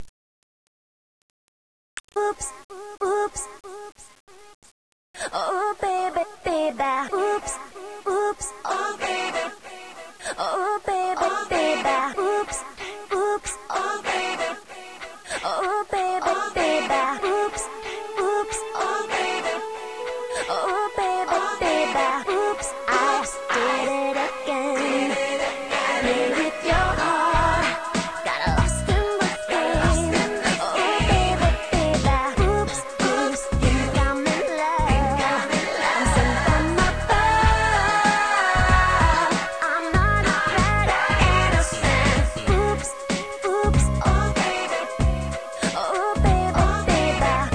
Re-mix